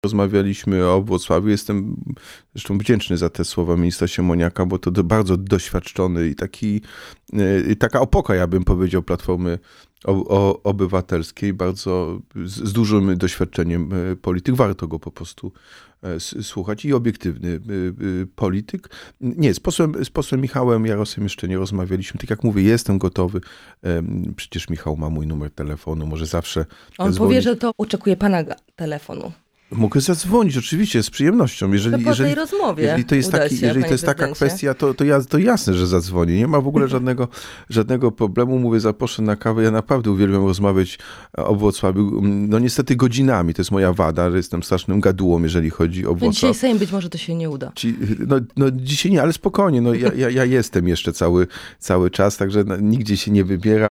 Pojawiły się spekulacje, że prezydent zostanie wiceministrem lub obejmie urząd wiceprezydenta Gdańska. Na naszej antenie Jacek Sutryk dementował te informacje.